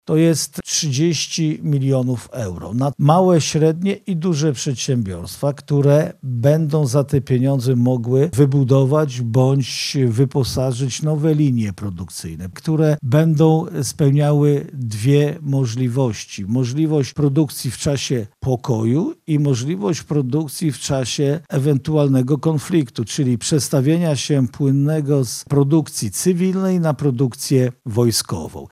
– Z tej alokacji będzie realizowany między innymi priorytet obronny – mówi marszałek województwa lubelskiego Jarosław Stawiarski.